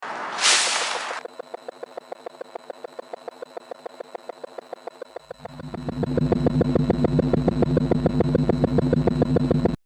Free SFX sound effect: Shapeshifting Static.
Shapeshifting Static.mp3